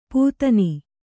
♪ pūtani